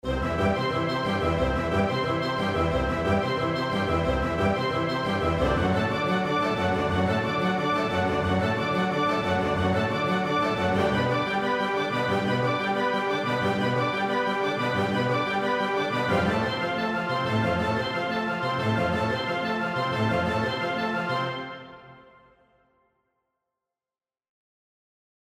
Zum Vergleich der Stereomix ohne SAD: